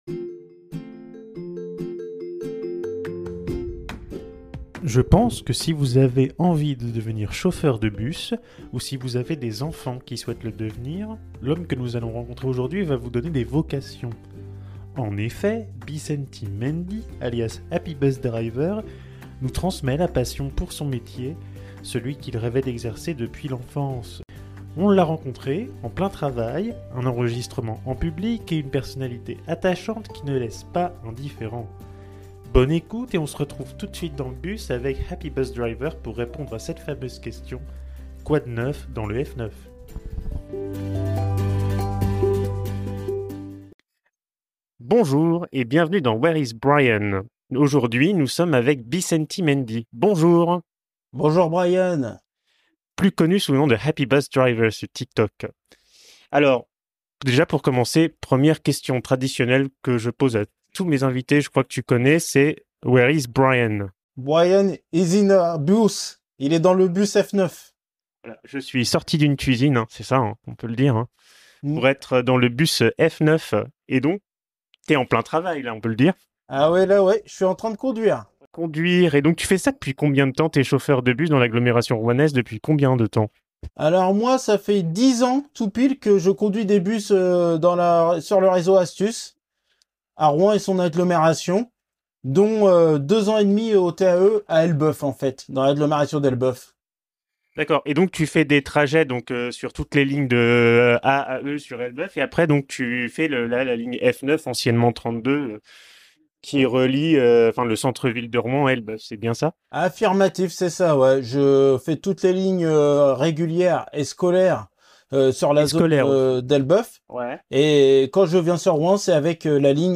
Nous l’avons rencontré en plein travail, un enregistrement en public et une personnalité attachante qui ne laisse pas indifférent !